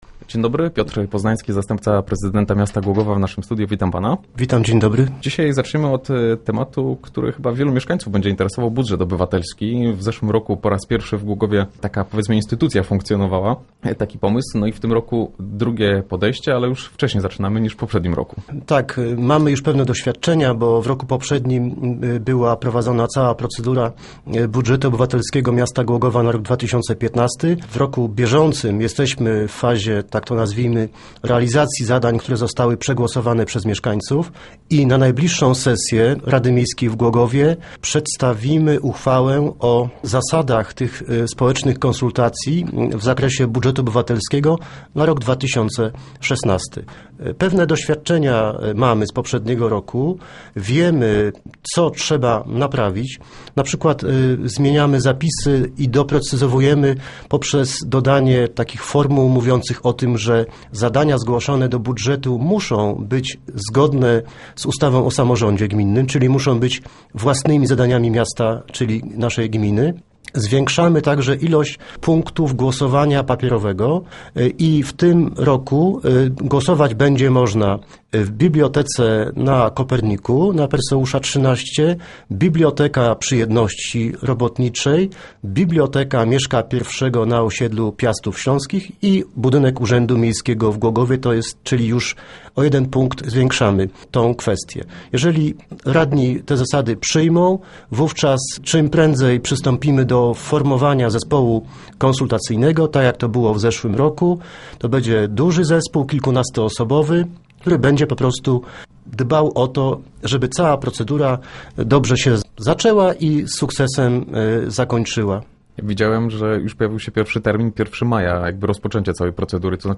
W tym roku cała procedura rozpocznie się dwa miesiące wcześniej, by głogowianie mieli więcej czasu na zgłaszanie propozycji, a potem na wybranie tych do realizacji. O szczegółach opowiada zastępca prezydenta Piotr Poznański.